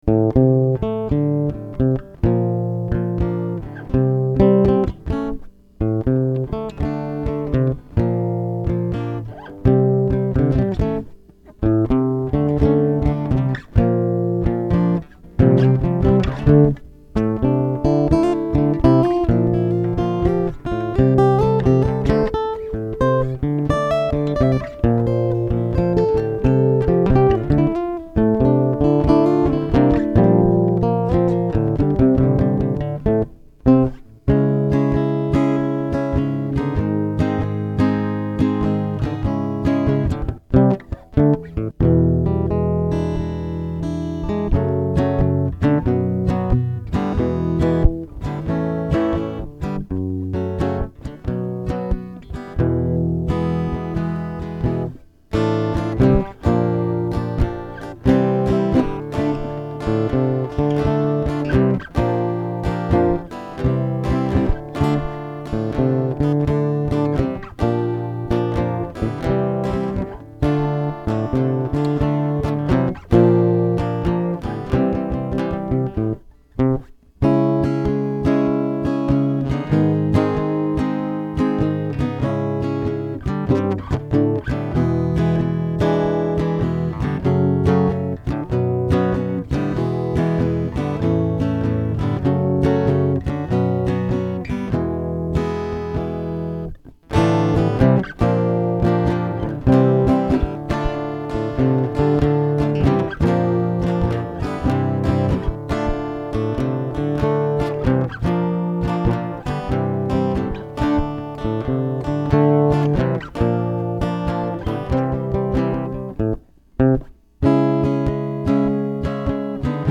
For the next measures, you will see two guitar parts.